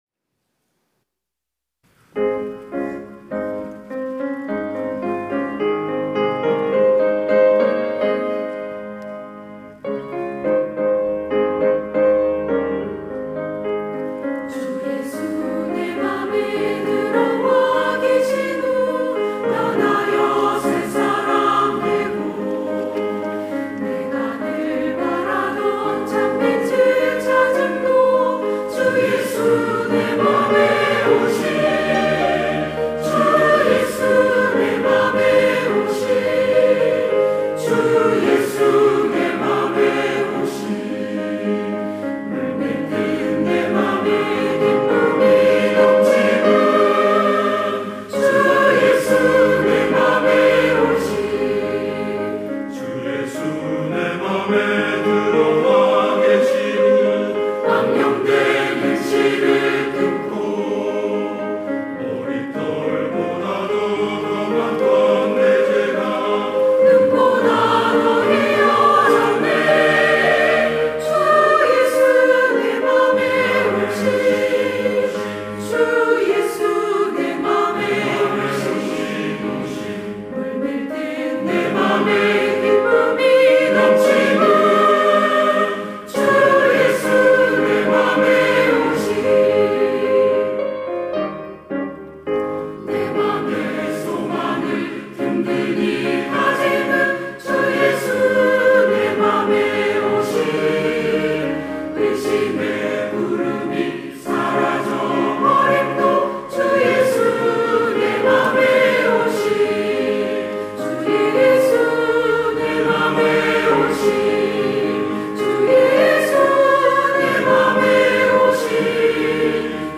시온(주일1부) - 주 예수 내 맘에 들어와
찬양대